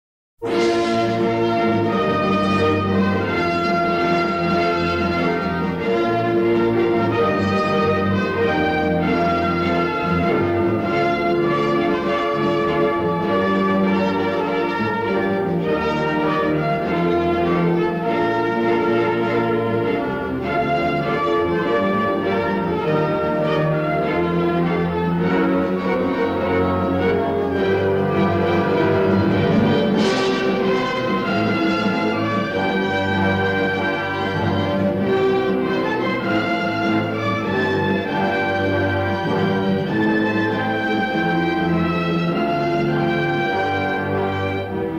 remastered from the original 1/4" stereo album tapes